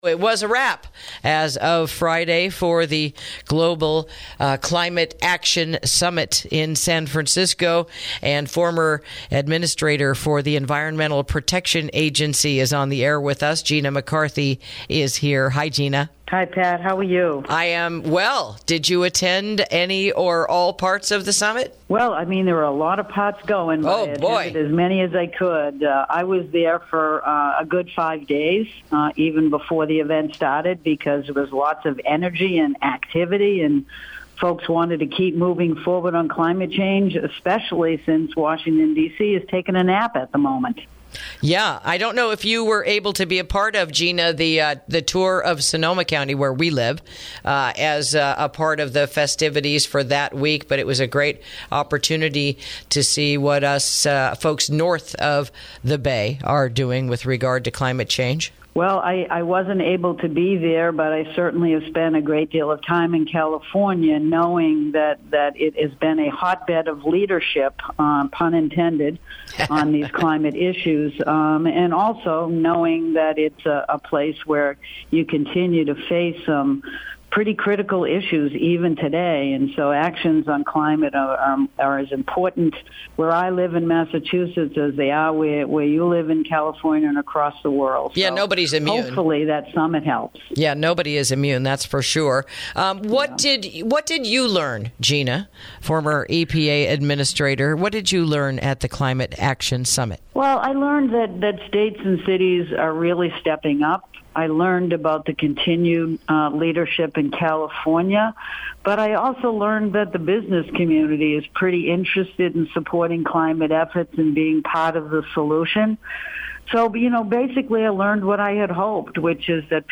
INTERVIEW: Final Look at the Global Climate Action Summit That Concluded Over the Weekend